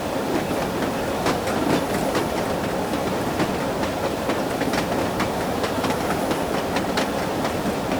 train-wheels-2.ogg